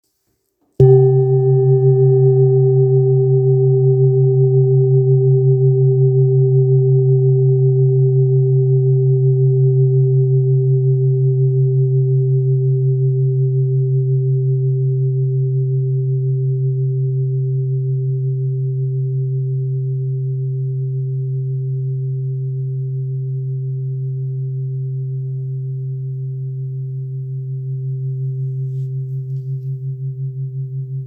Kopre Singing Bowl, Buddhist Hand Beaten, Antique Finishing, Select Accessories, 18 by 18 cm,
Material Seven Bronze Metal
It is accessible both in high tone and low tone .
In any case, it is likewise famous for enduring sounds.